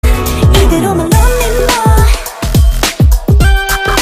DBM_RF2_82_Guitar_Fx_OneShot_Apologies_Emin